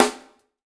LG SNR 2  -L.wav